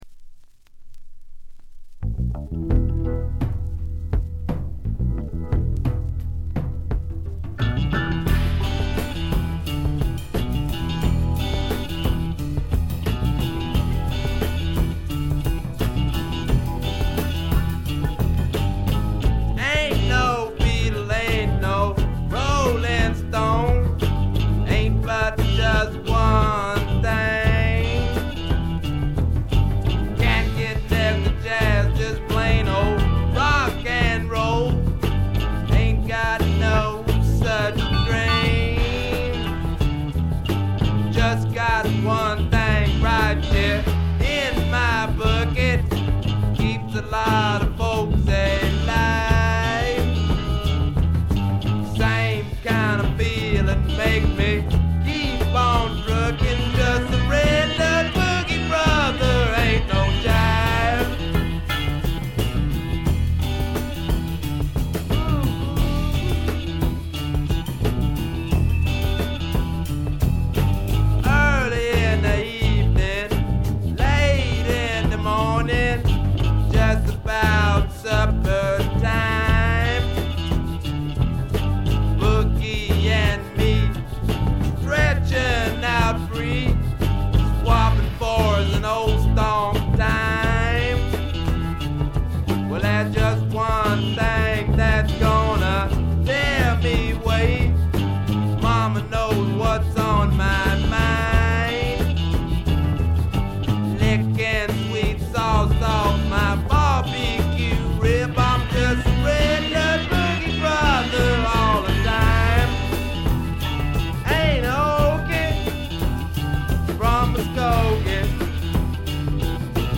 軽微なチリプチがほんの少し。
いうまでもなく米国スワンプ基本中の基本。
オリジナルUS盤のぶっとい音をお楽しみ下さい！
試聴曲は現品からの取り込み音源です。